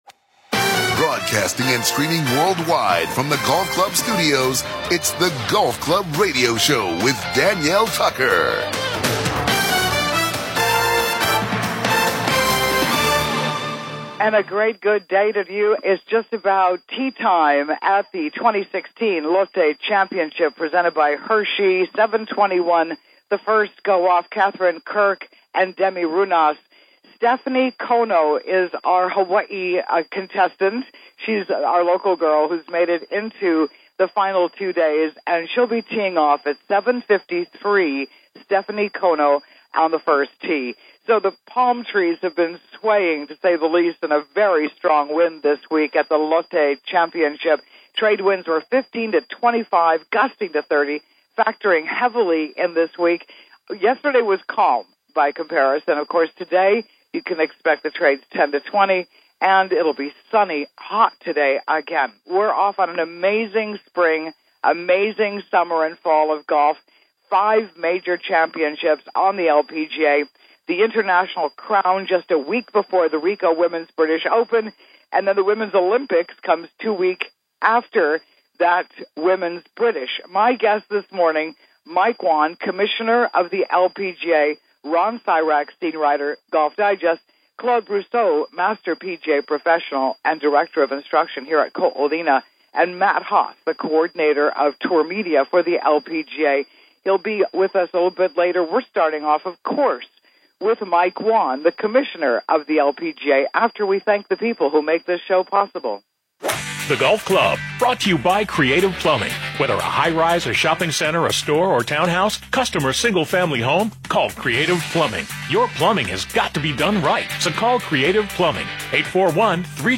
Live from The Lotte Championship Ko Olina Resort Golf Course Honolulu, Hawaii